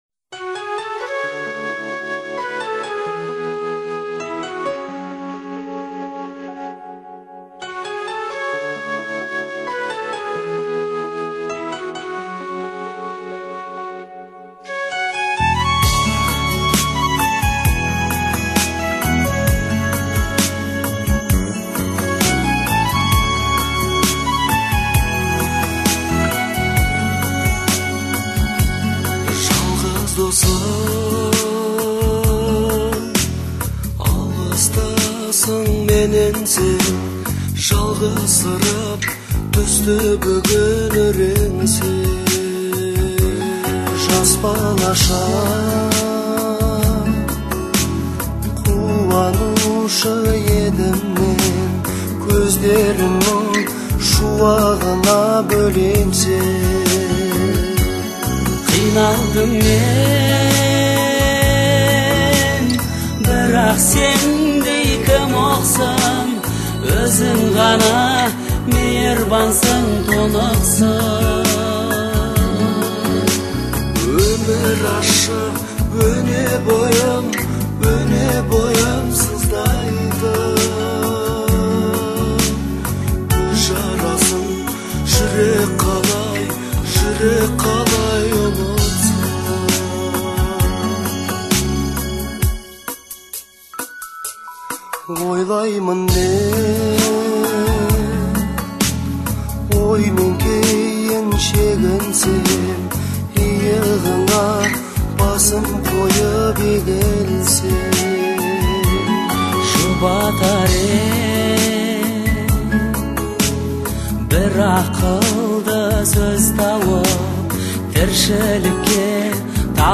исполненная в жанре поп.